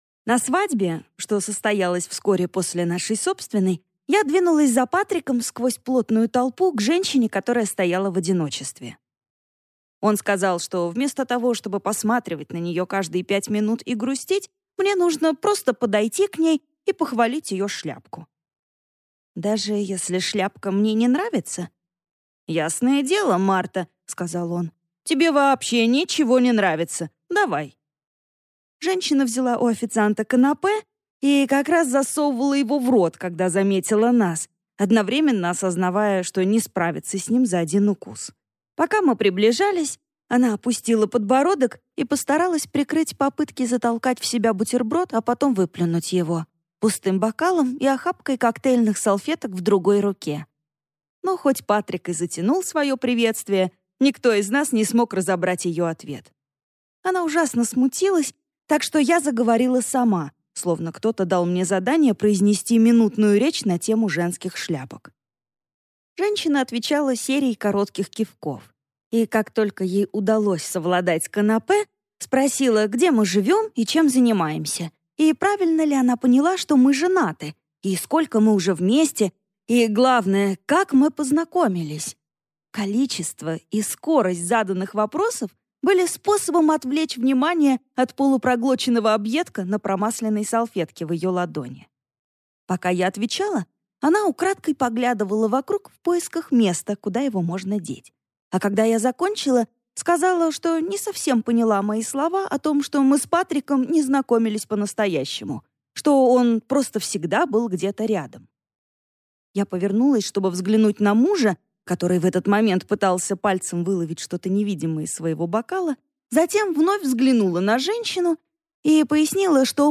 Aудиокнига И в горе, и в радости